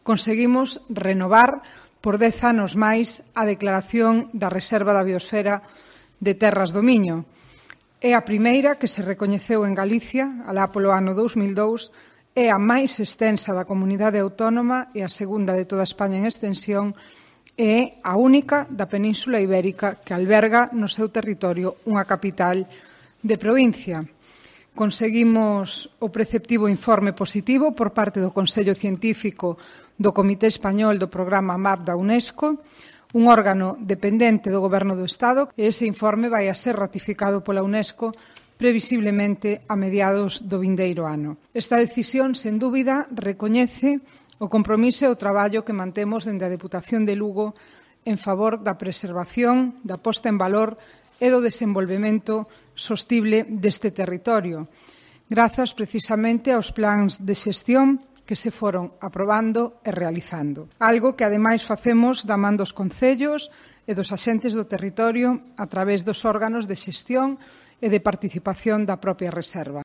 El anuncio lo hizo en rueda de prensa la diputada Pilar García Porto